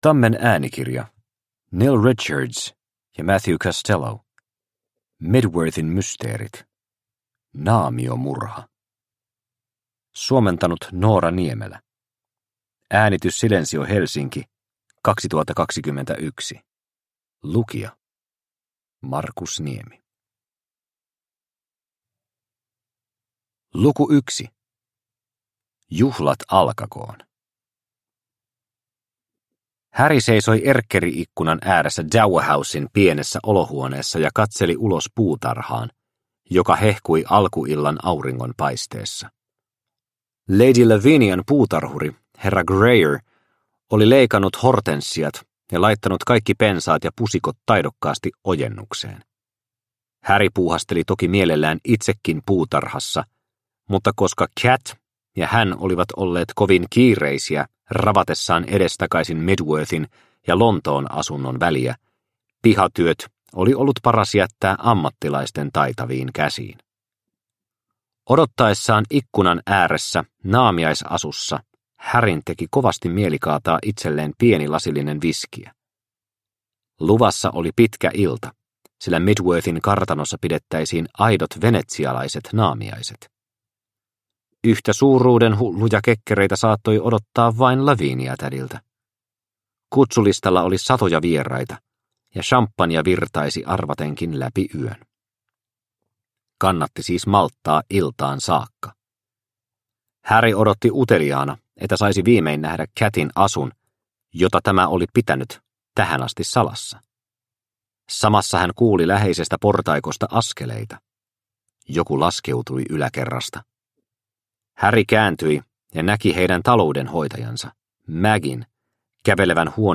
Mydworthin mysteerit: Naamiomurha – Ljudbok – Laddas ner